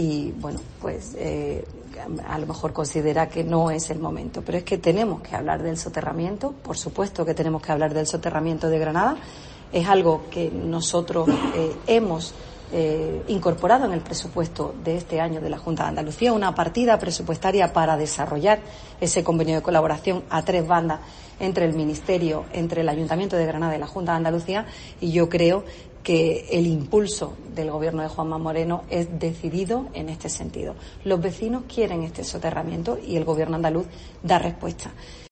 Rocío Díaz, consejera de Fomento de la Junta de Andalucía